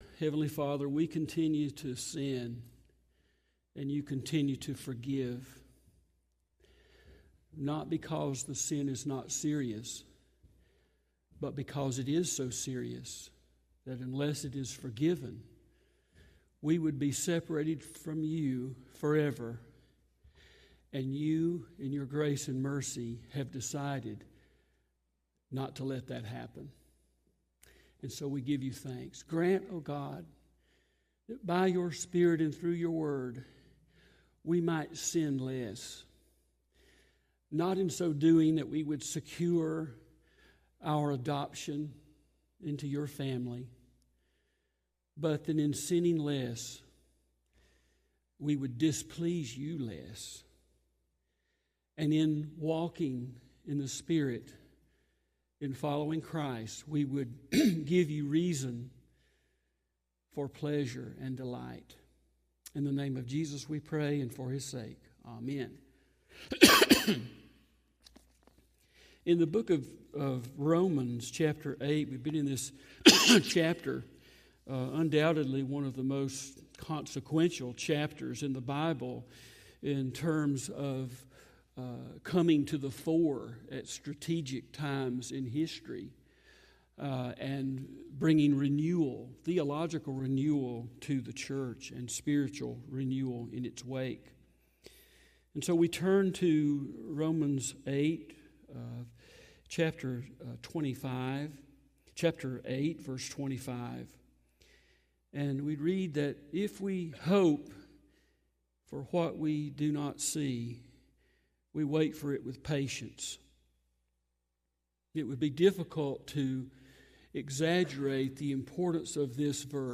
Sermons | Smoke Rise Baptist Church